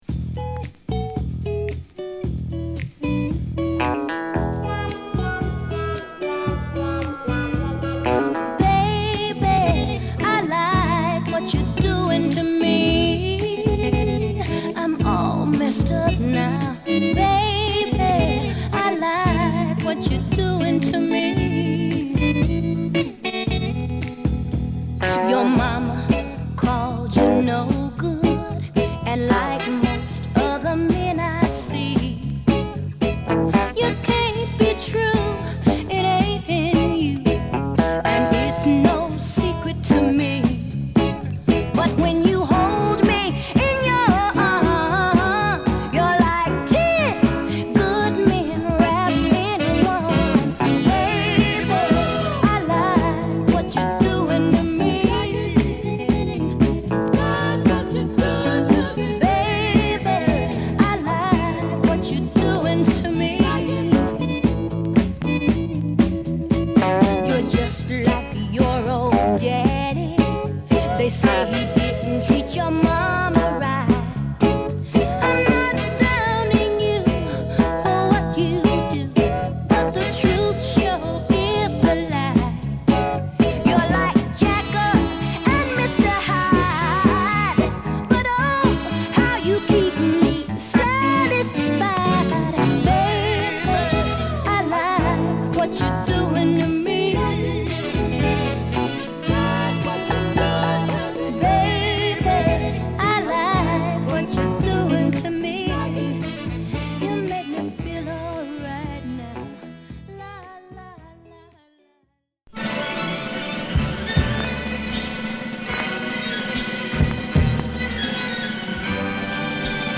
晴れやかなノーザン・ソウル
コブシの効いたサザン・ソウル